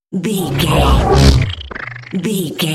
Sci fi alien energy pass by 819
Sound Effects
futuristic
intense
pass by